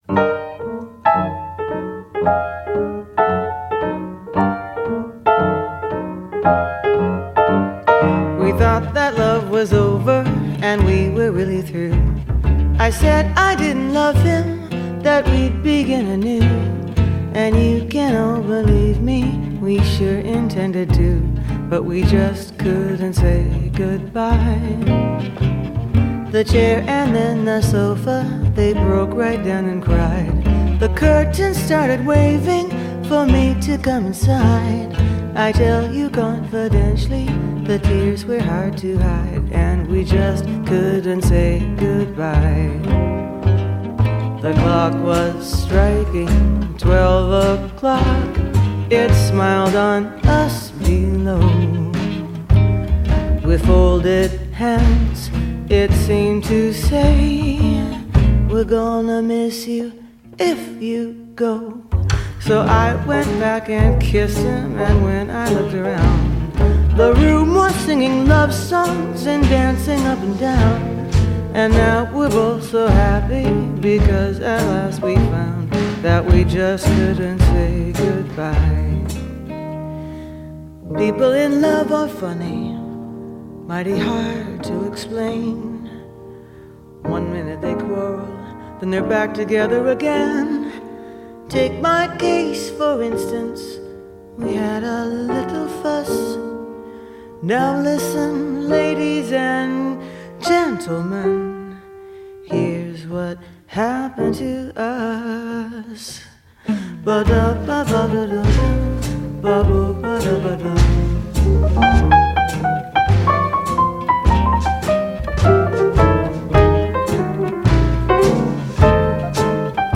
Jazz, Pop